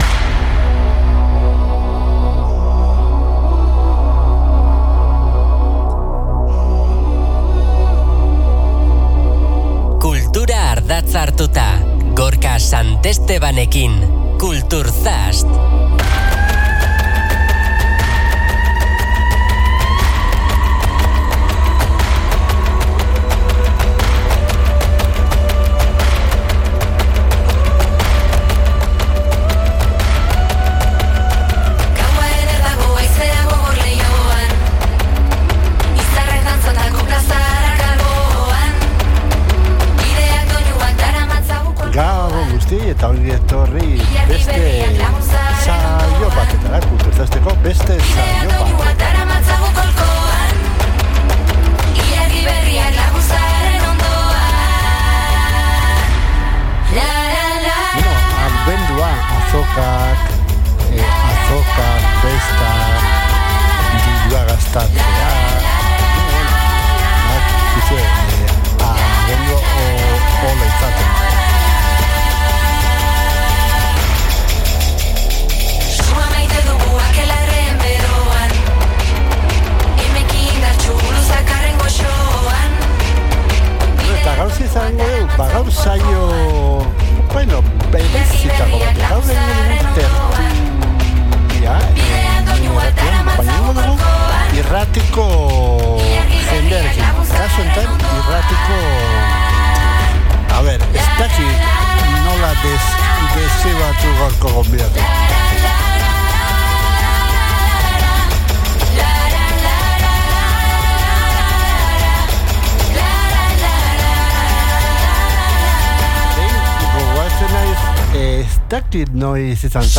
Agendako hitzordu garrantzitsuak, kultur-egileei elkarrizketak eta askoz ere gehiago bilduko ditu ordubeteko tartean.